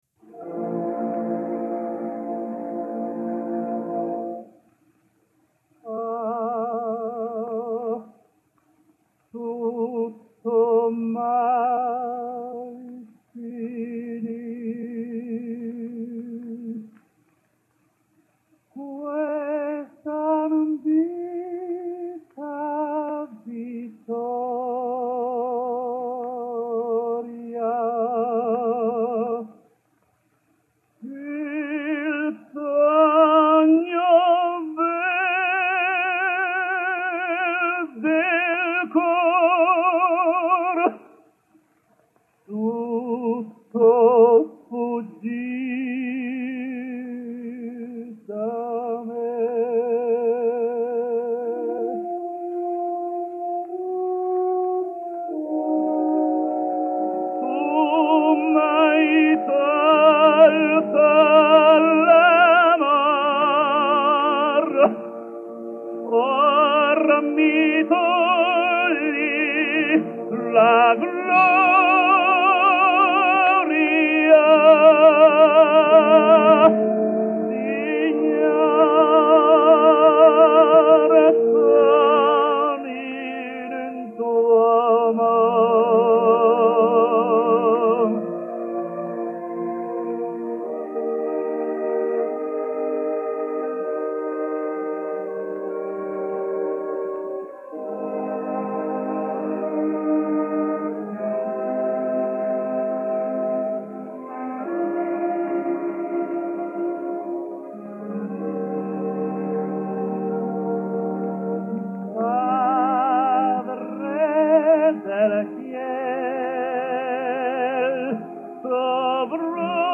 Puerto Rican Tenor.